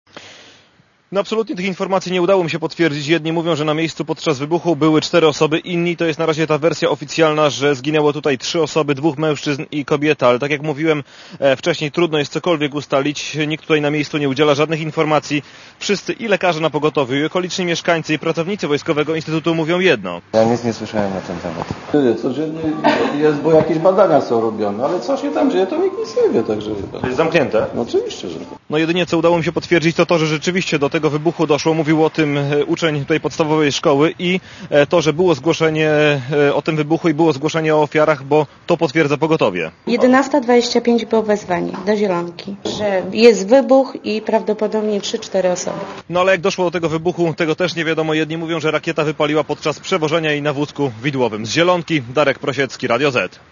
Posłuchaj relacji reportera Radia Zet z Zielonki (208 KB)